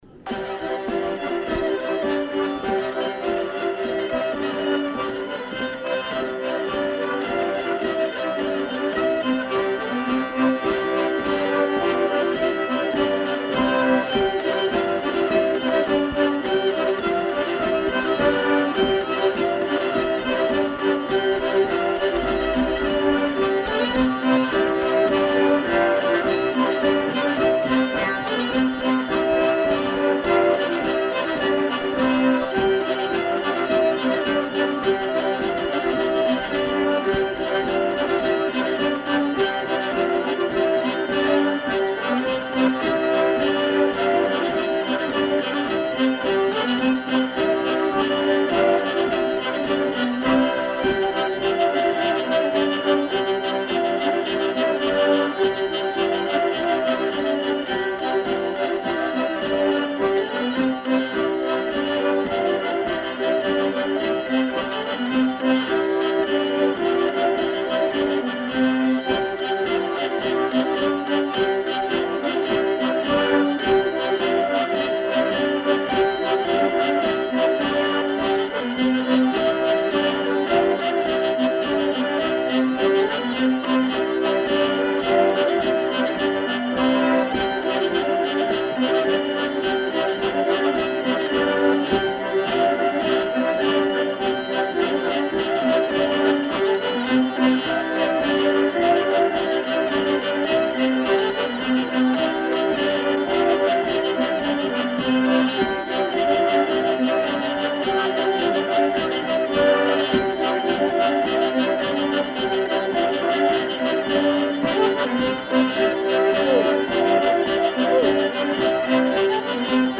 Aire culturelle : Savès
Département : Gers
Genre : morceau instrumental
Instrument de musique : accordéon diatonique ; violon ; vielle à roue
Danse : rondeau